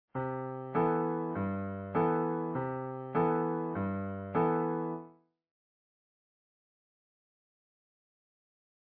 Bossa Nova. In this next idea we use a tonic function 13th chord to set up the core bossa guitar pulse. And while our 13th is built into the chord, we have the option of alternating our bass with a root / Five motion, so common to the bossa / Latin grooves adored by the dancers.